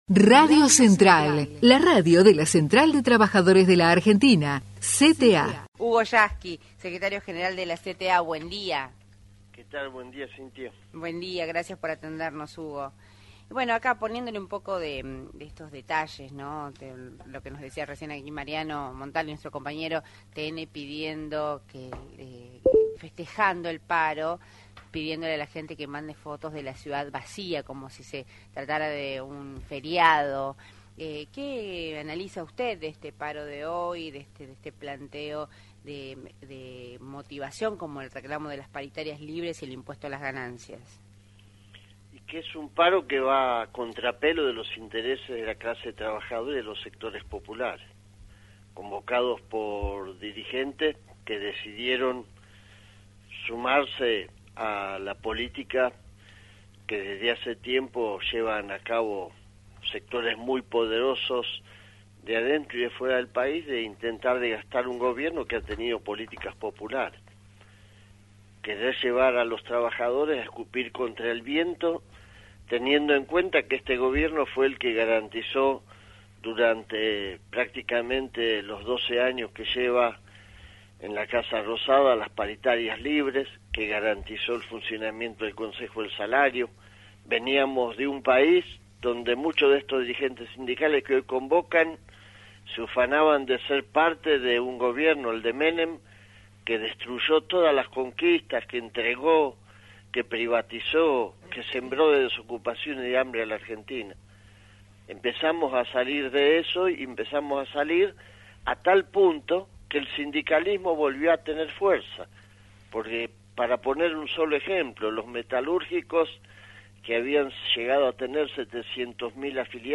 HUGO YASKY entrevistado en RADIO NACIONAL